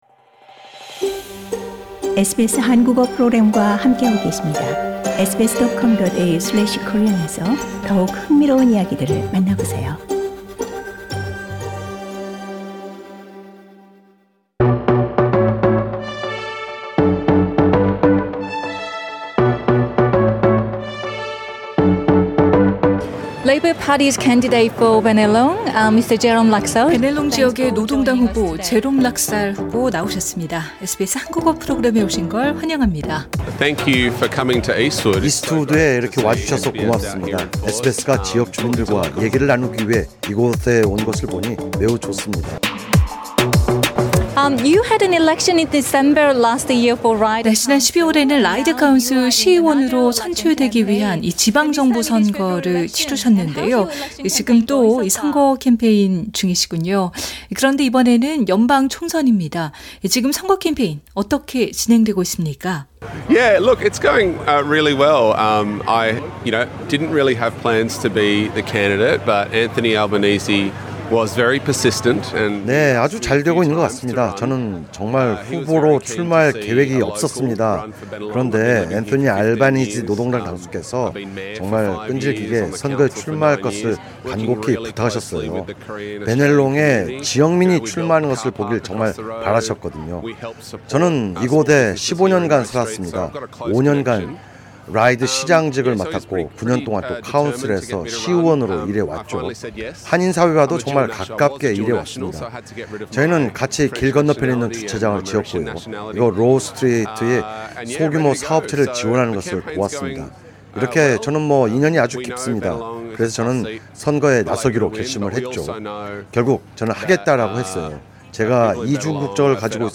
Election Exchange 라는 이름으로 저희 SBS 한국어 프로그램 뿐 아니라 북경어, 광동어, 힌디어 프로그램이 현장에서 총선 출마 후보자들을 인터뷰하는 기회를 가진 겁니다.